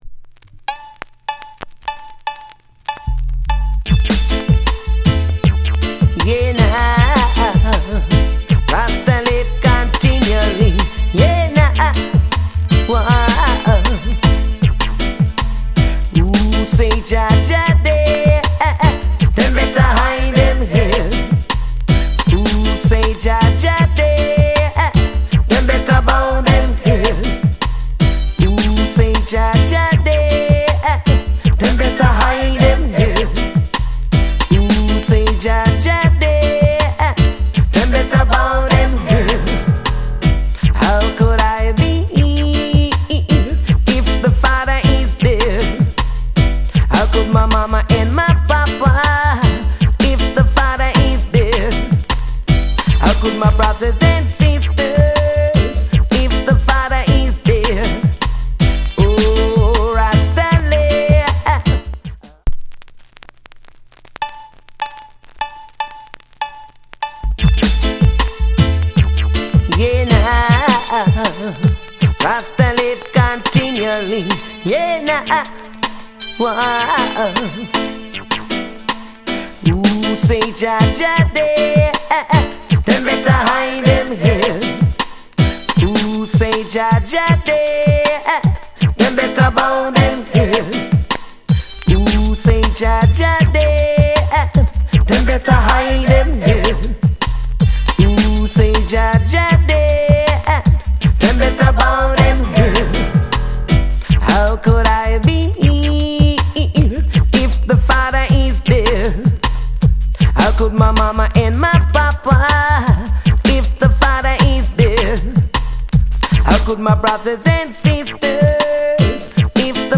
Killer Digital Roots!!